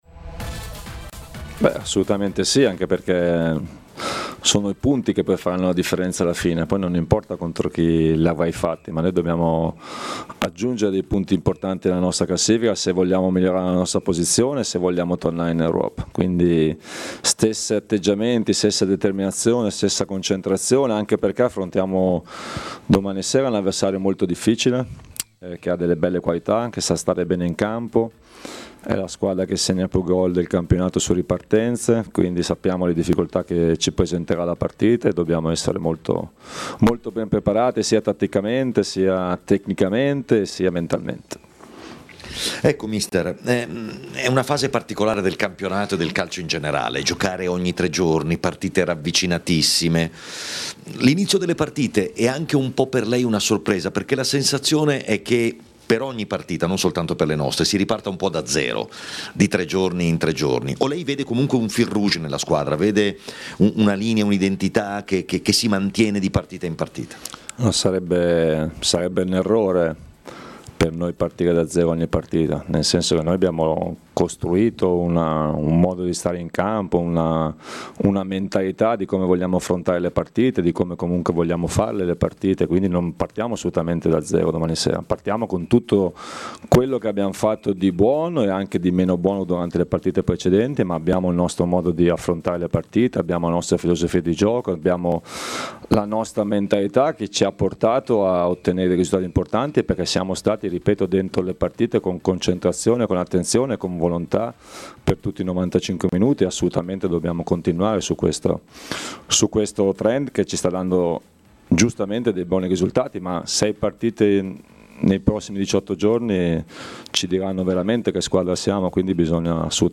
A Maracanà, nel pomeriggio di TMW Radio, è intervenuto mister Gianni Di Marzio, che ha parlato dei temi del momento.